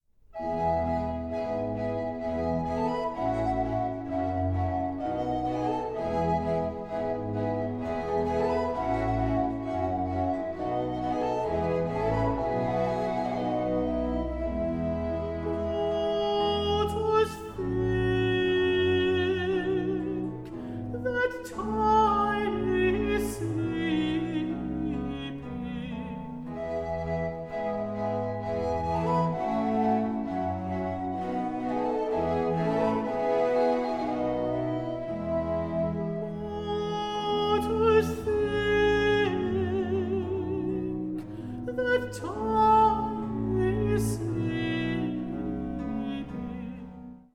countertenor
on authentic instruments
Recorded 6-8 September 2013 at Menuhin Hall, Surrey, UK